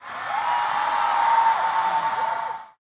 bl_crowd_cheer.ogg